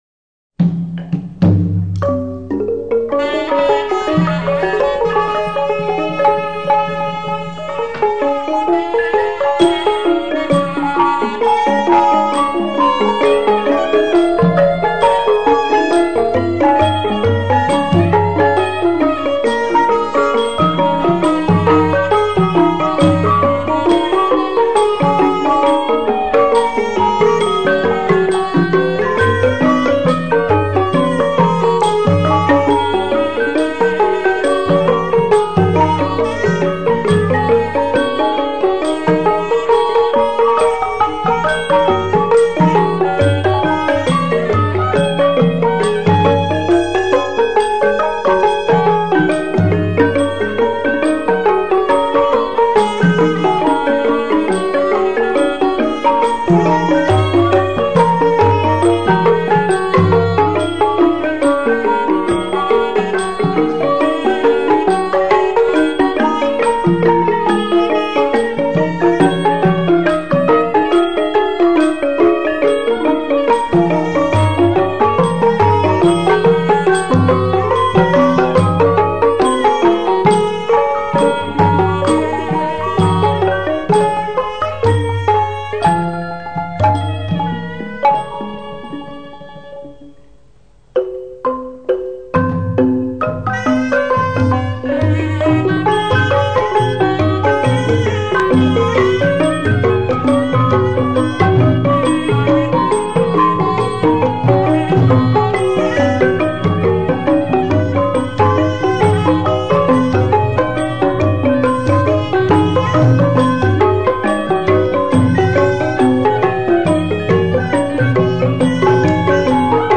ភ្លេងប្រេីក្នុងពិធីផ្សេងៗ
ភ្លេងប្រេីក្នុងពិធីផ្សេងៗបុណ្យទក្ខិណានុប្បទាន ជ្រៃក្រហឹុម